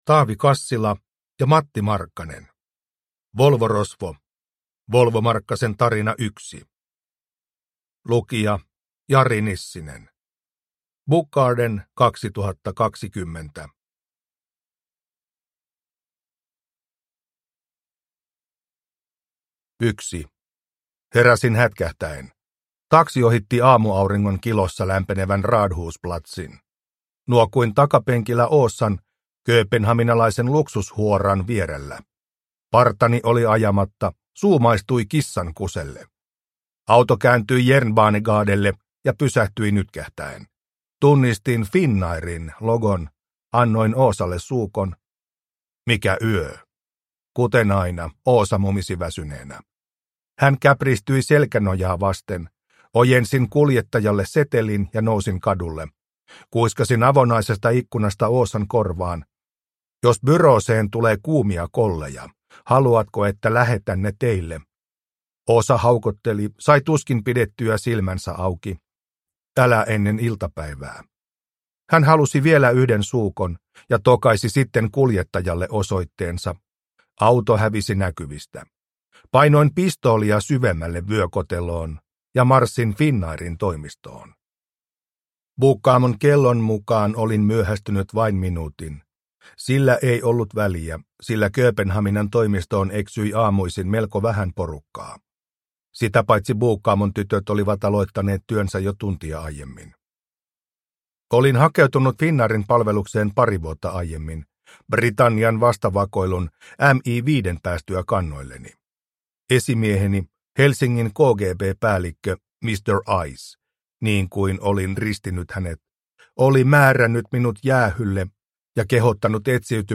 Volvo-rosvo – Ljudbok